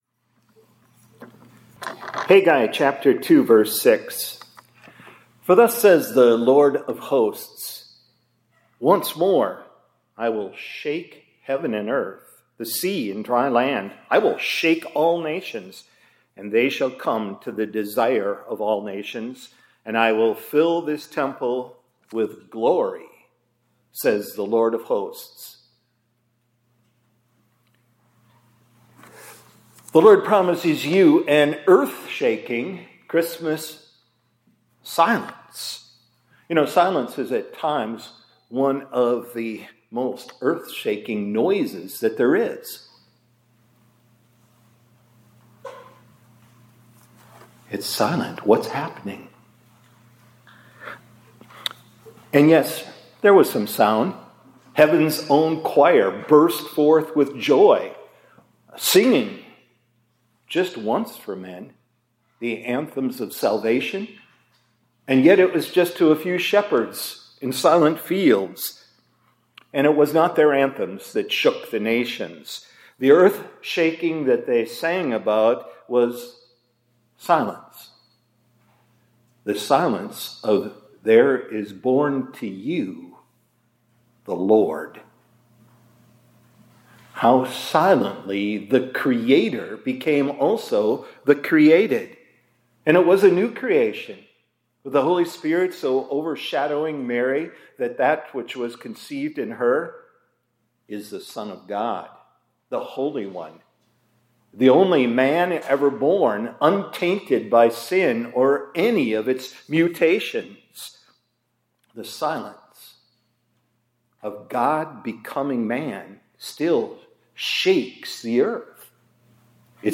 2025-12-09 ILC Chapel — The LORD Promises You an Earthshaking Christmas Silence